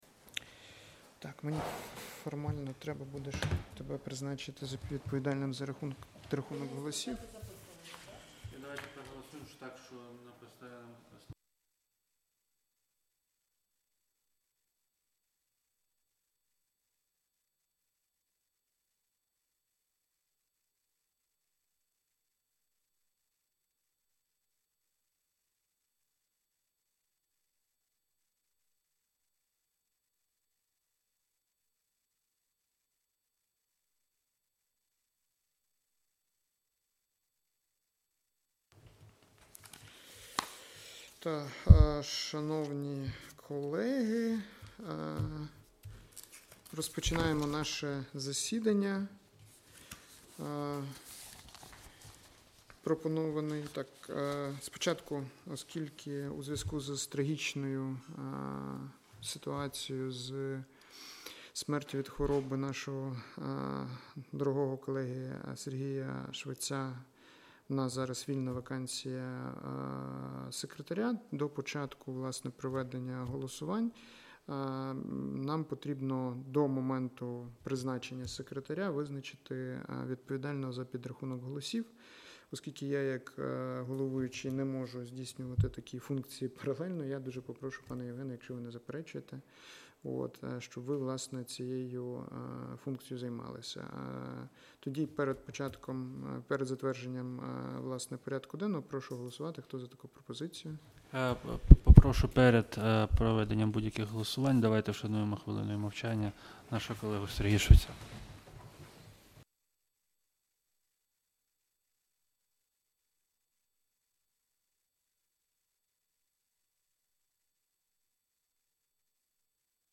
Аудіозапис засідання Комітету від 4 червня 2025р.